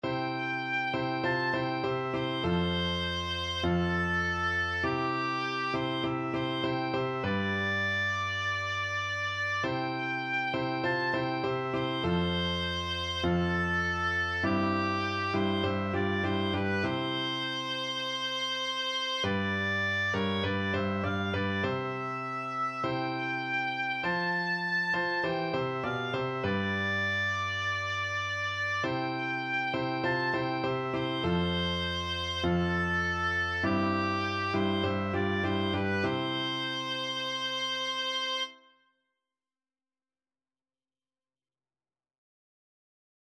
Christian
C major (Sounding Pitch) (View more C major Music for Oboe )
4/4 (View more 4/4 Music)
Classical (View more Classical Oboe Music)